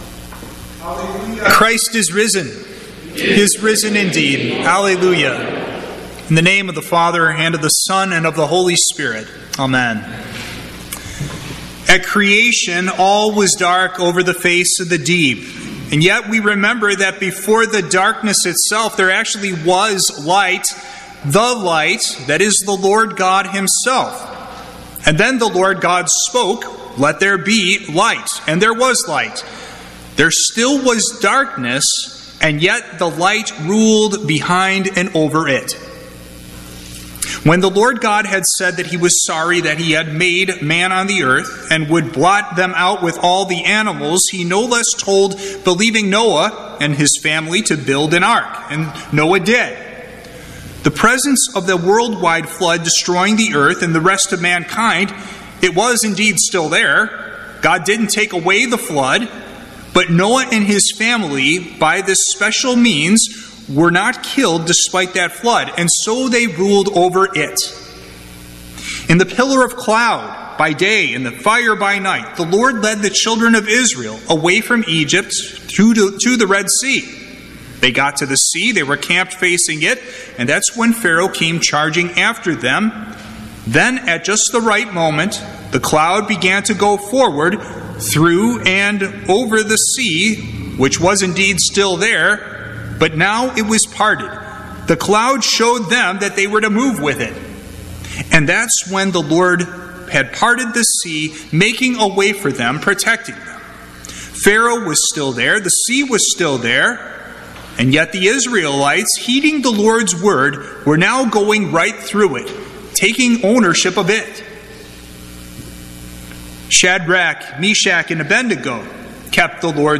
Sermon
Easter Vigil